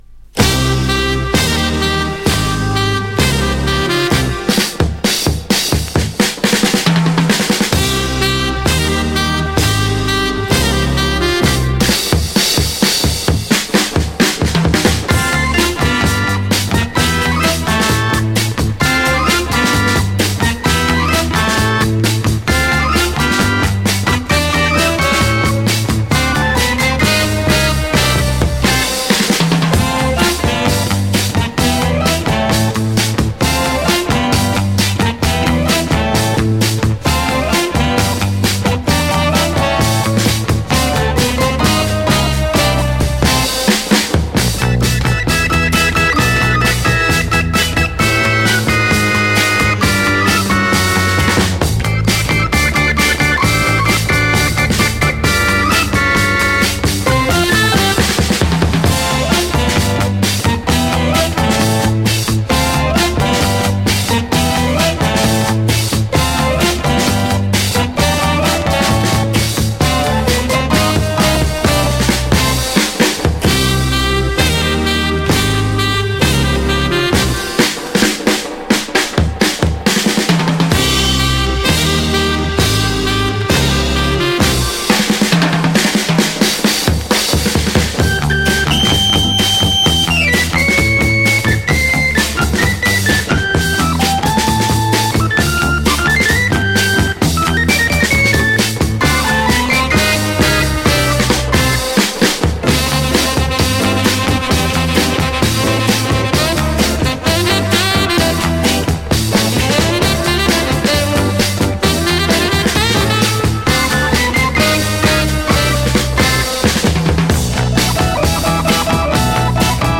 Lounge germany